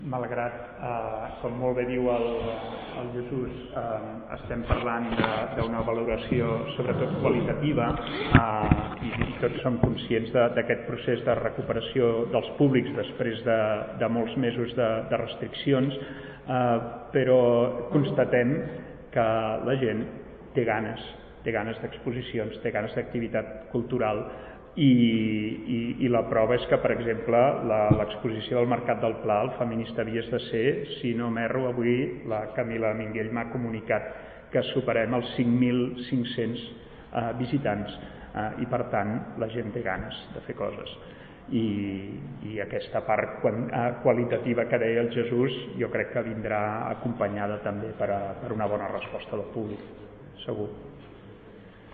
tall-de-veu-del-regidor-de-ciutat-i-cultura-jaume-rutllant-sobre-la-nit-dels-museus-2021